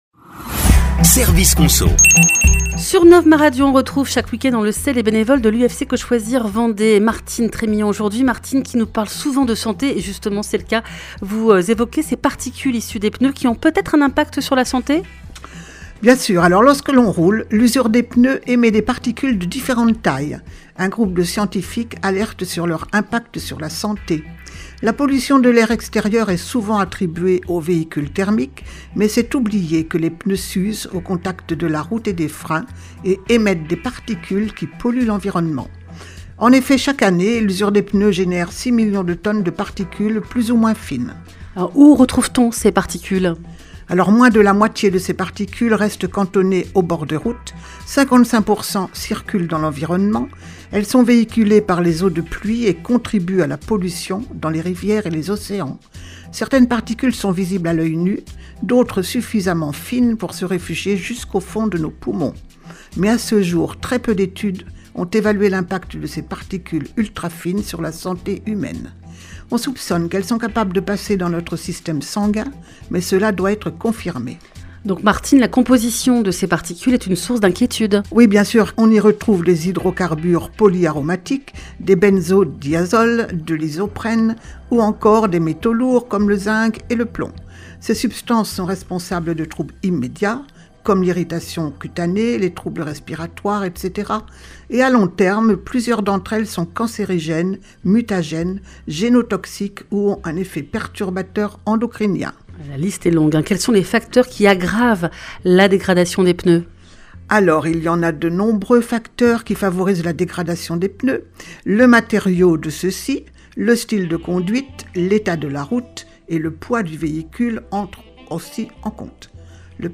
Chaque semaine, sur Nov Ma Radio, retrouvez les bénévoles de l’UFC Que Choisir Vendée pour des chroniques argumentées !